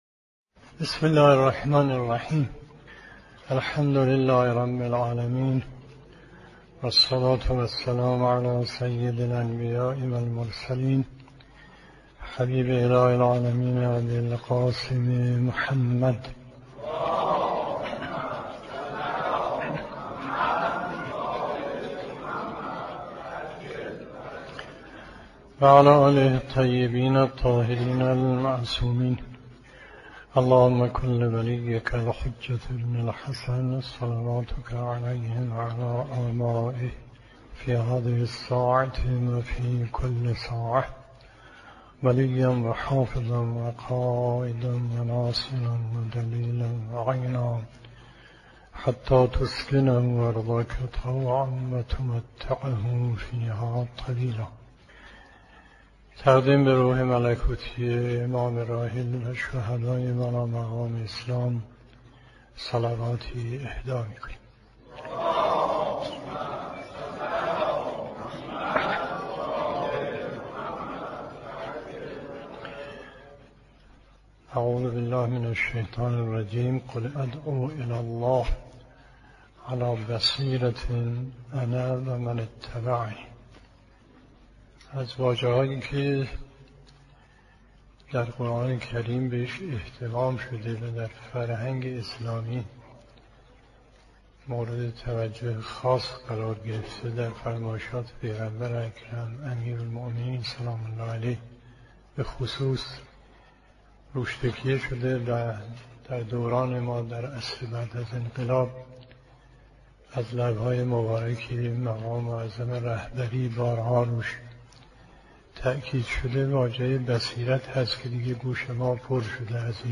سخنرانی آیت الله مصباح یزدی درباره ریشه های عدم بصیرت